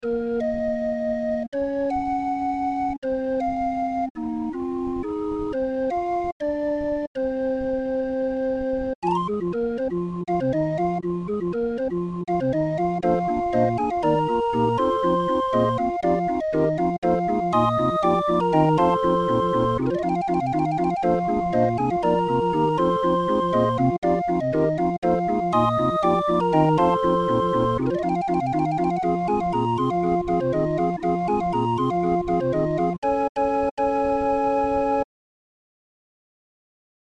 Musikrolle 31-er Raffin